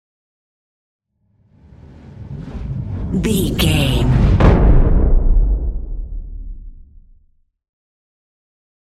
Cinematic whoosh to hit deep
Sound Effects
Atonal
dark
futuristic
intense
tension
woosh to hit